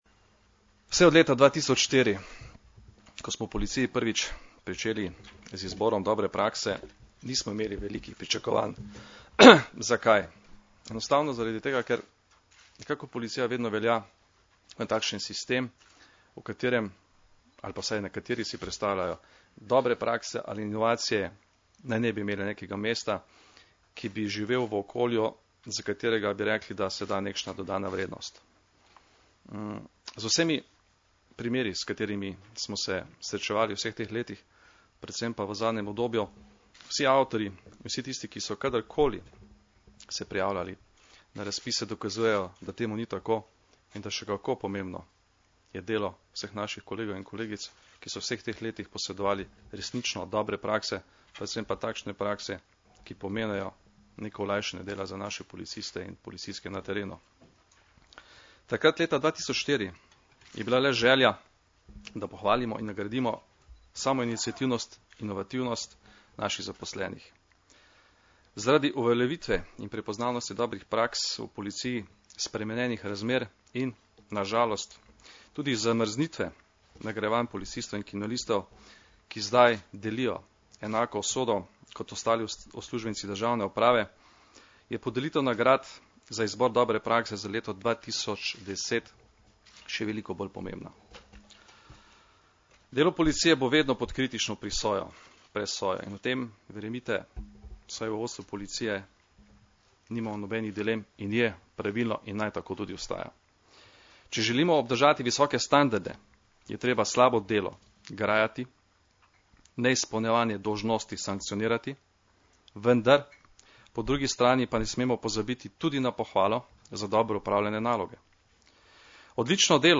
Generalni direktor policije Janko Goršek je danes, 4. maja 2011, v Policijski akademiji podelil priznanja avtorjem treh najbolje ocenjenih dobrih praks v policiji za leto 2010.
Zvočni posnetek izjave Janka Gorška (mp3)